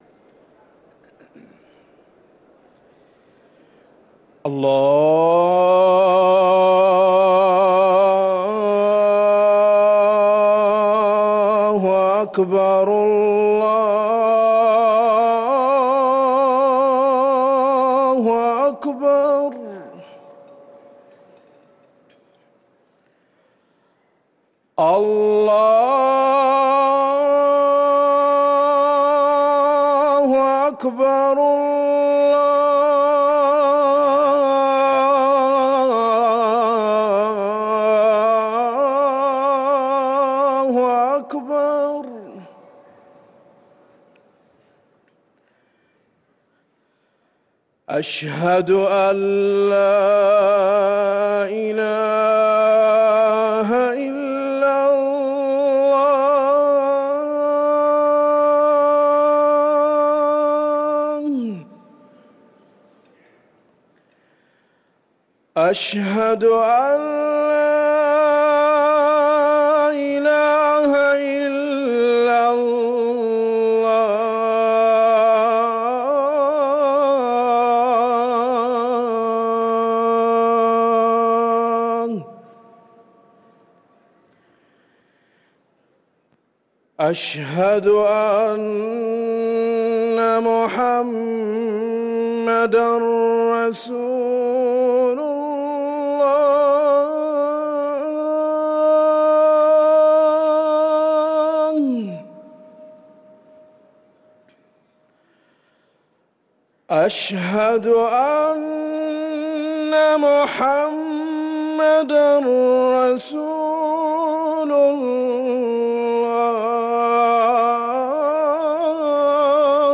أذان العصر
ركن الأذان 🕌